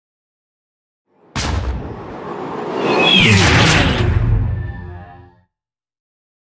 Double hit with whoosh sci fi
Sound Effects
Atonal
dark
futuristic
intense
tension
woosh to hit